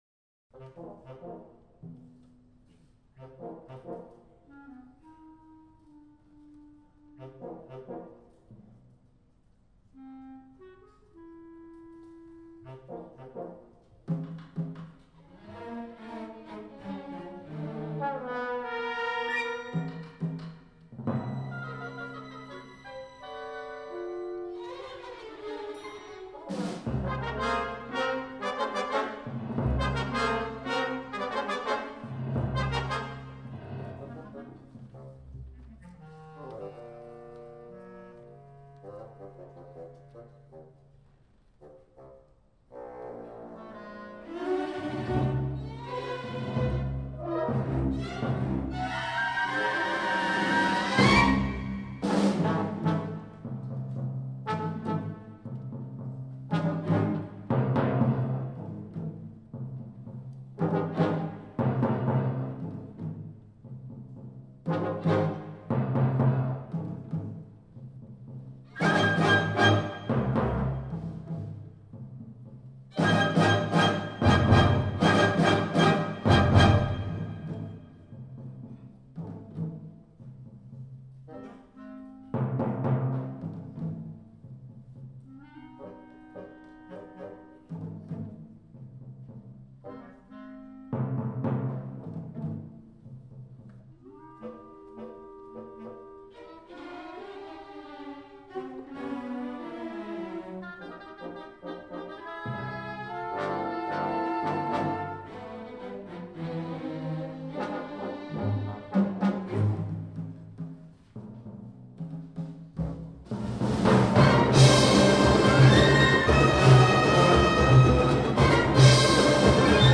música académica uruguaya